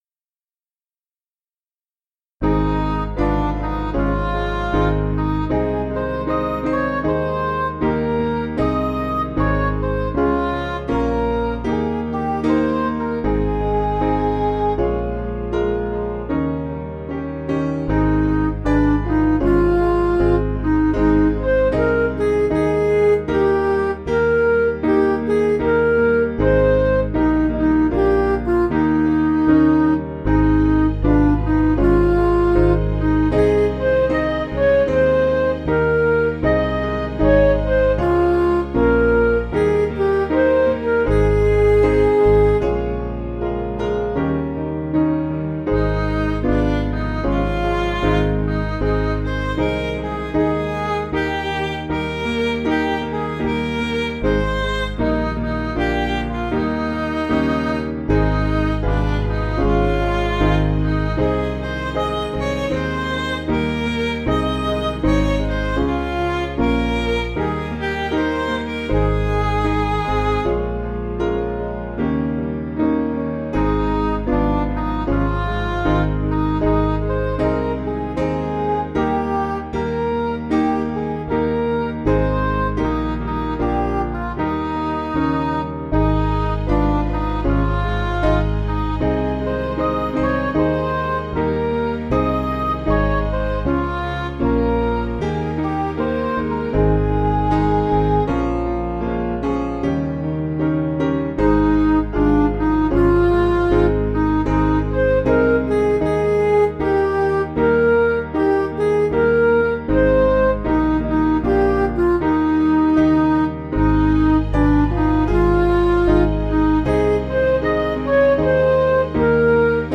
(BH)   4/Ab
Vocals and Organ   265.1kb Sung Lyrics 2.7mb